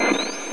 Cipher being drawn (NES game)